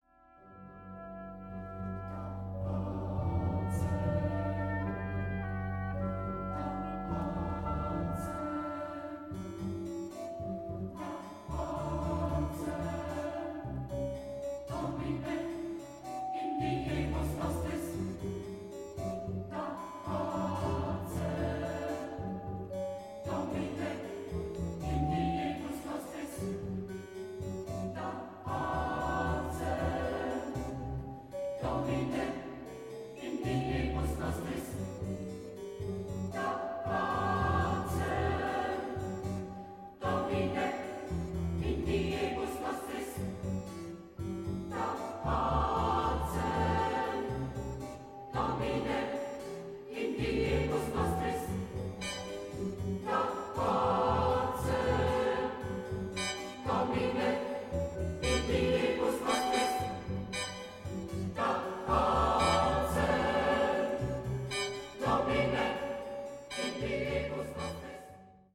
Genre-Style-Forme : Sacré ; Motet ; Psaume ; contemporain
Type de choeur : SATB  (4 voix mixtes )
Tonalité : mi mode de mi